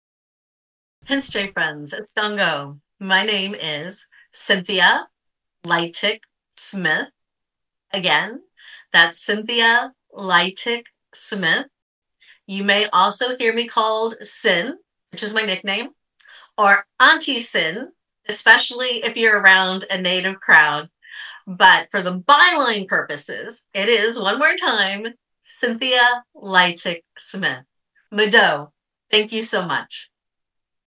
Audio Name Pronunciation
A recording introducing and pronouncing Cynthia Leitich Smith.